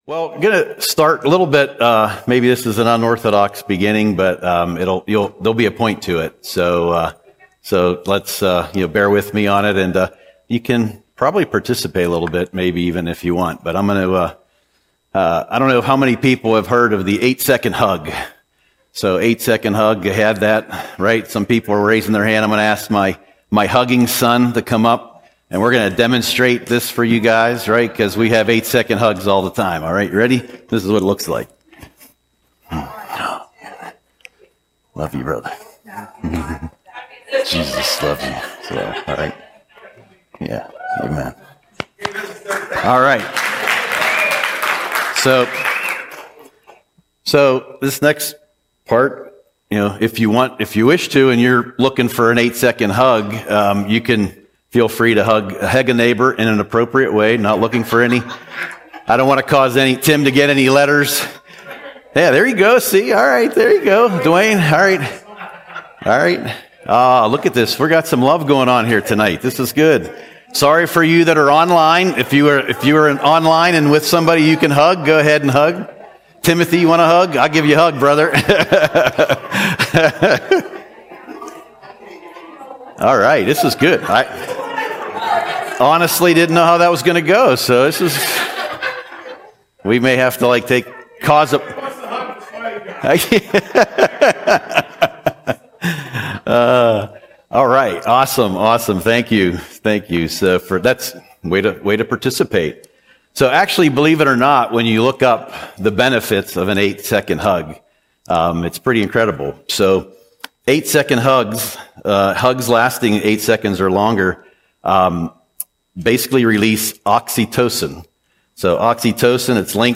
Audio Sermon - June 11, 2025